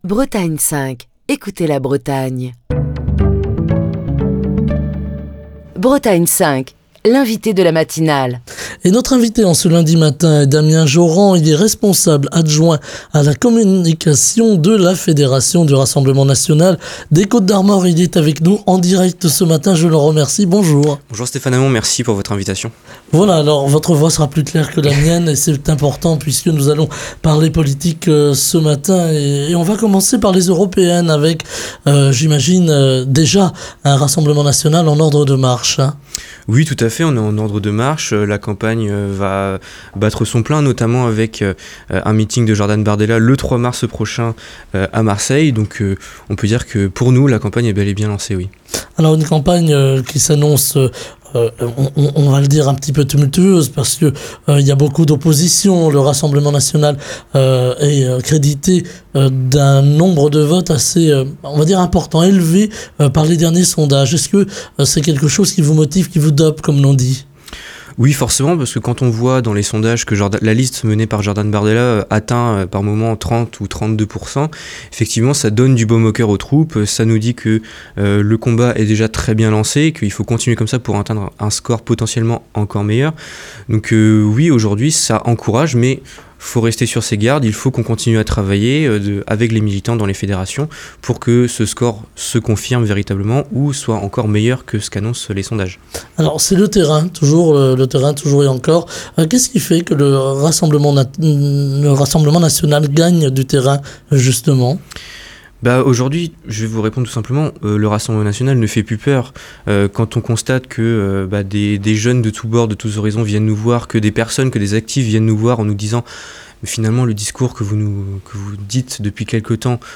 Au sommaire de cet entretien de la matinale, la crise agricole - à une semaine de l'ouverture du Salon de l'agriculture, les agriculteurs menacent de reprendre les actions si les réponses du gouvernement ne sont pas au niveau des attentes, la crise de la pêche qui impacte la Bretagne, la campagne pour les élections européennes avec Fabrice Leggeri, ex-directeur de Frontex, l'agence chargée du contrôle des frontières, qui rejoint la liste du Rassemblement national, la mort de l'opposant russe Alexeï Navalny, le conseil d'Emmanuel Macron au RN pour la cérémonie d'entrée au Panthéon du héros de la Résistance Missak Manouchian, et enfin, le débat sur la liberté d'expression et la représentativité des opinions dans les médias avec "l'affaire" CNews. Écouter Télécharger Partager le podcast Facebook Twitter Linkedin Mail L'invité de Bretagne 5 Matin